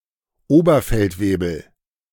Oberfeldwebel (German: [ˈoːbɐˌfɛltveːbl̩]
De-Oberfeldwebel.ogg.mp3